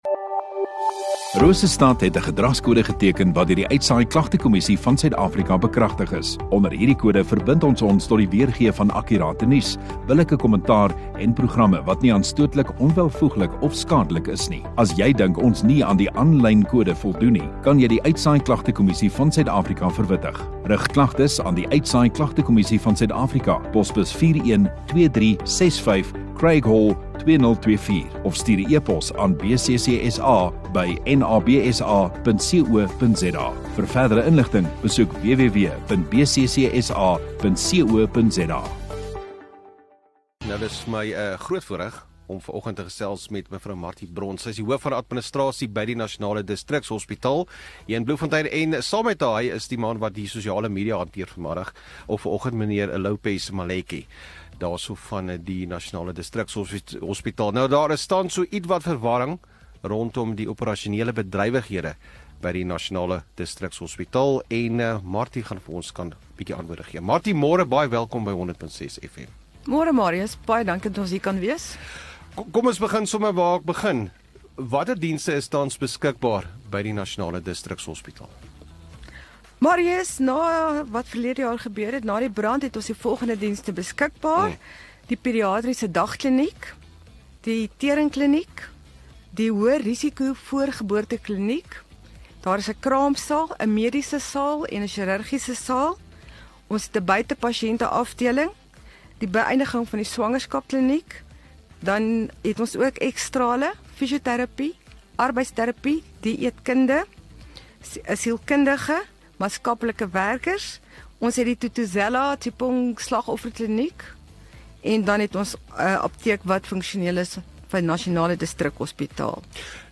View Promo Continue Radio Rosestad Install Gemeenskap Onderhoude 26 Jun Nasionale Distrikshospitaal